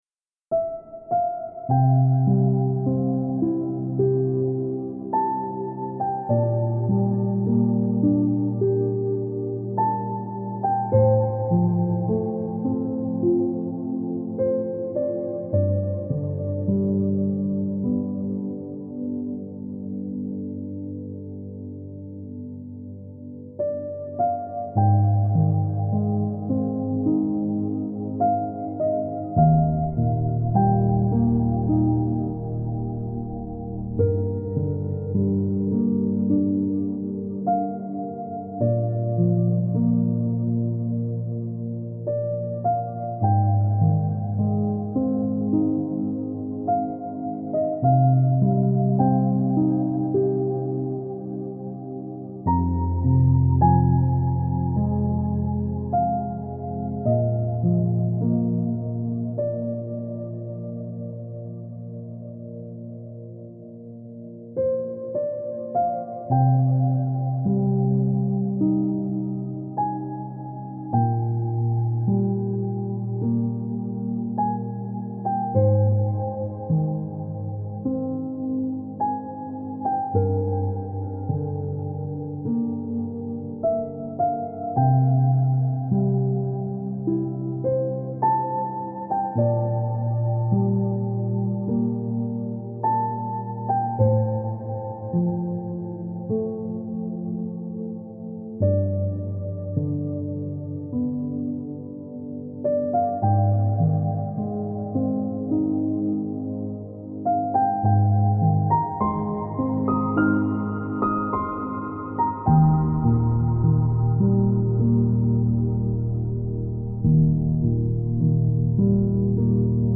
это мелодичная композиция в жанре эмбиент